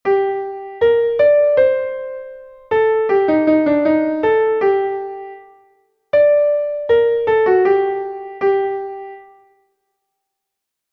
Cando aparece esta equivalencia, o pulso sempre é o mesmo.